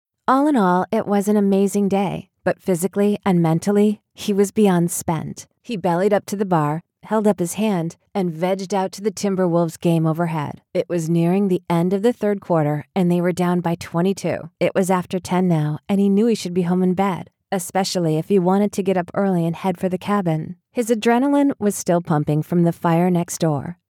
Thriller